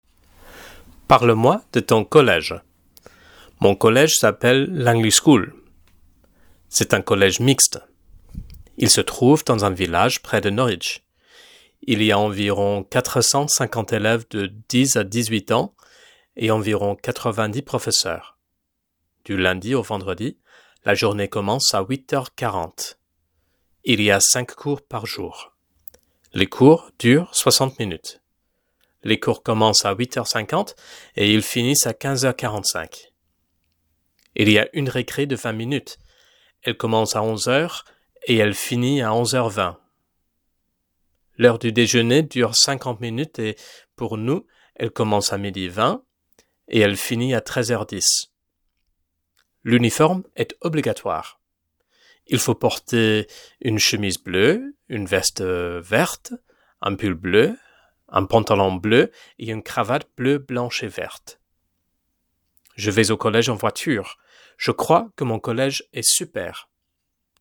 Activité : Pratiquer la prononciation.  Écoute l’enregistrement et imite la prononciation.